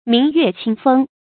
明月清風 注音： ㄇㄧㄥˊ ㄩㄝˋ ㄑㄧㄥ ㄈㄥ 讀音讀法： 意思解釋： 只與清風、明月為伴。